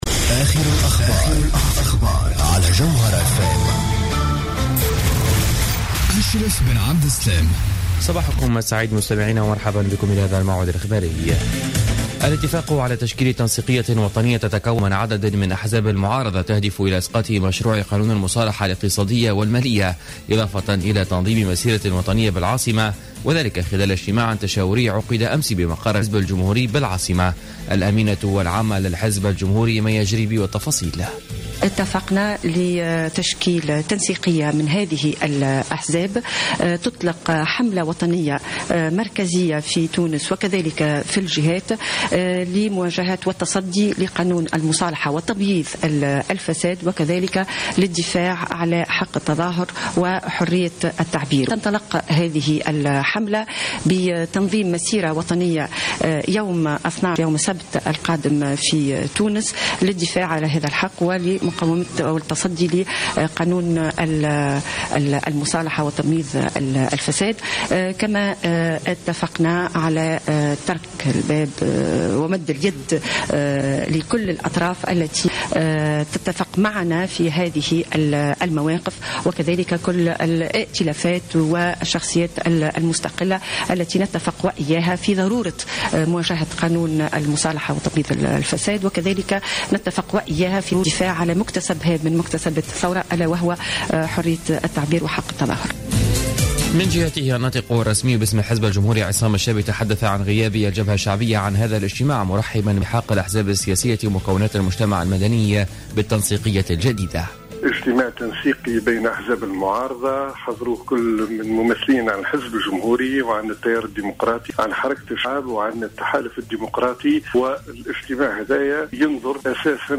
نشرة أخبار منتصف النهار ليوم السبت 5 سبتمبر 2015